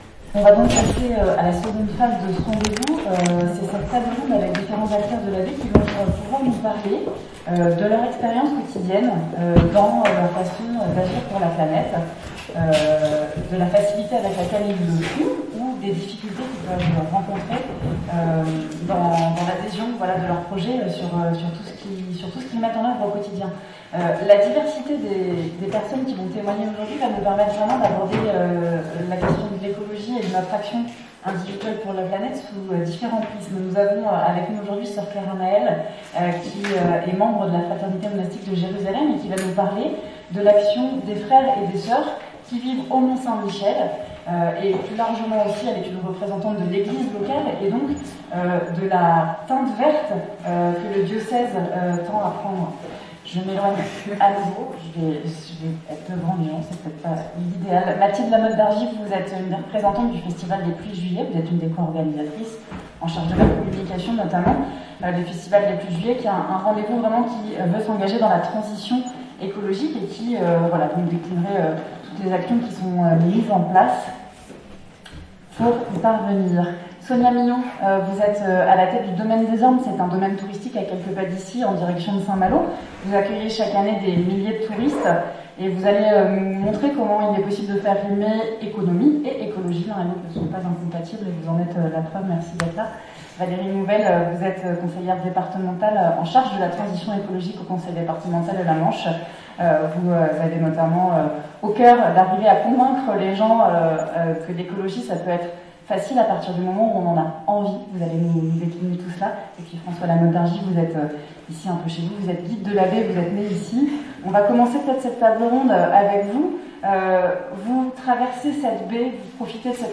Agir pour la planete : table ronde — Pélerins Mont Saint Michel